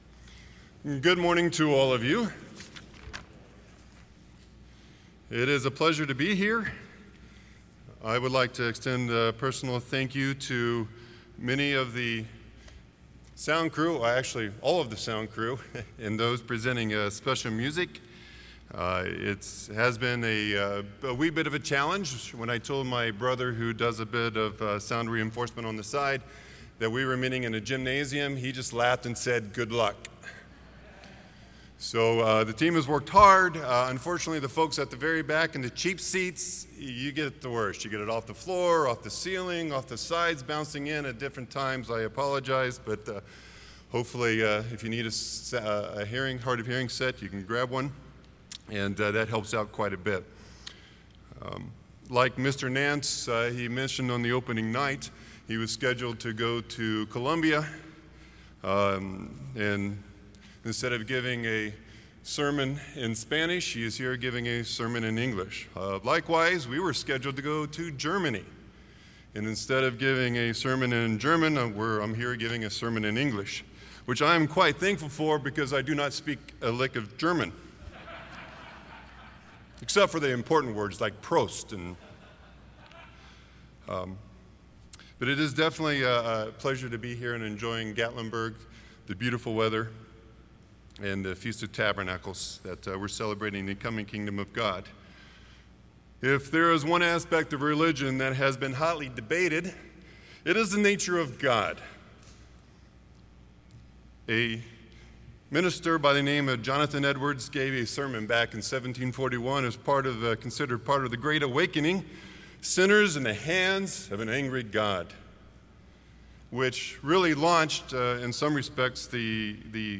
This sermon was given at the Gatlinburg, Tennessee 2020 Feast site.